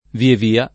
vie via [ vie v & a ]